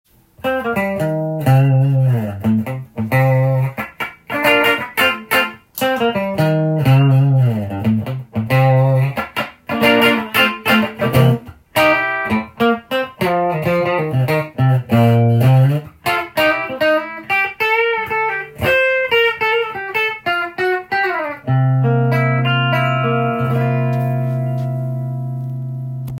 ブースターになので　もの凄く音が変化するわけではありませんが、
やはり手作り感と丁寧さがにじみ出た音がしました。